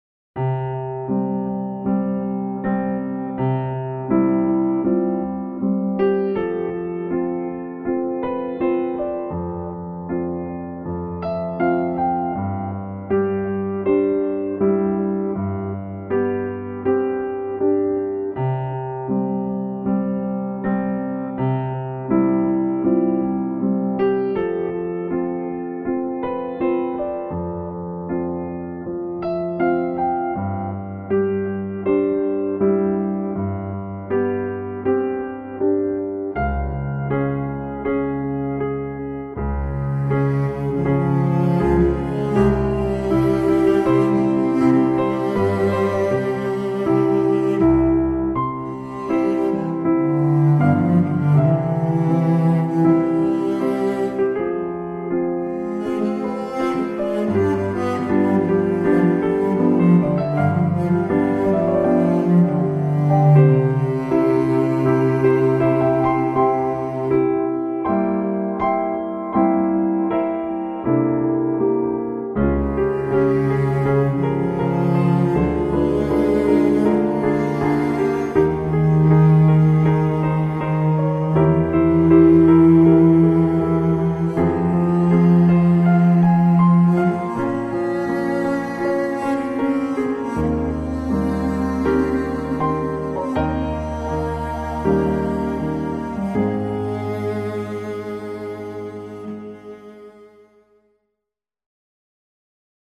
piano - intimiste - romantique - aerien - melodieux